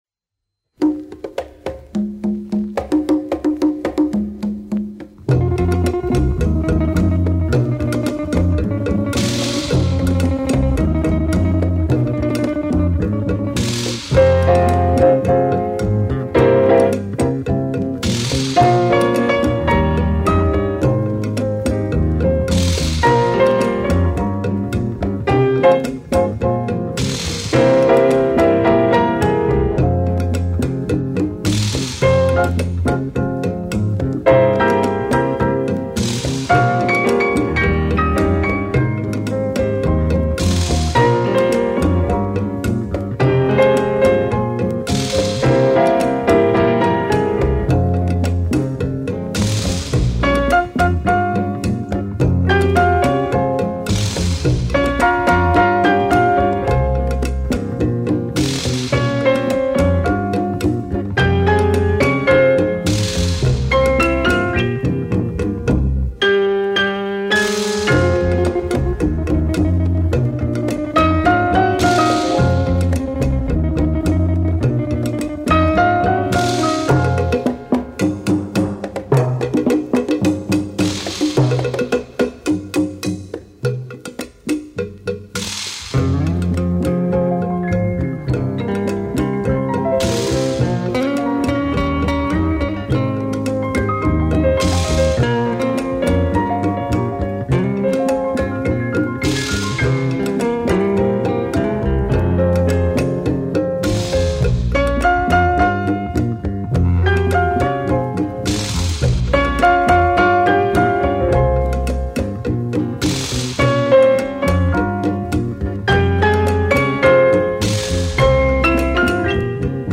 Фортепьяно